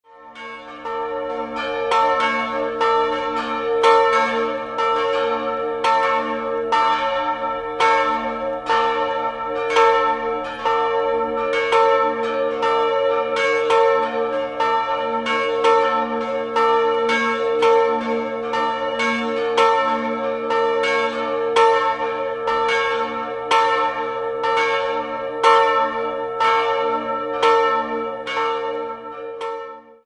3-stimmiges TeDeum-Geläute: a'-c''-d''